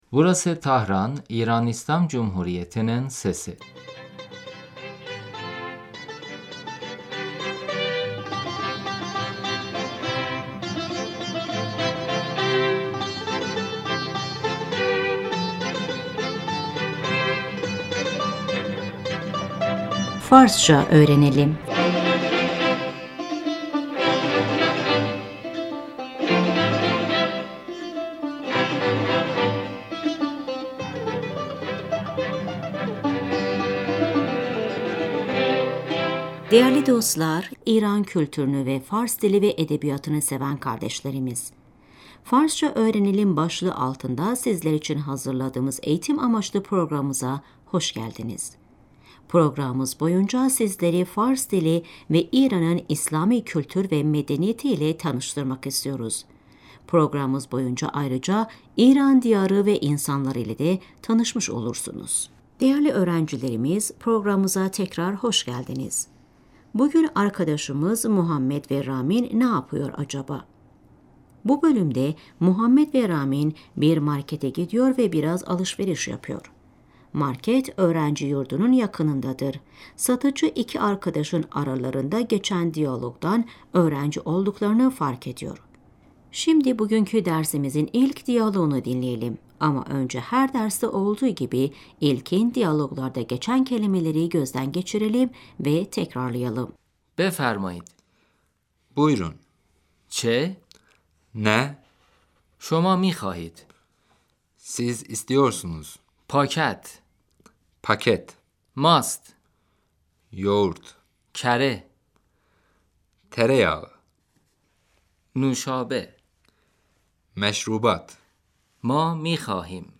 در فروشگاه صدای جا به جا کردن وسایل و سر و صدای خیابان Markette, eşyaların sesi, caddenin sesi فروشنده - بفرمایید ، چه می خواهید ؟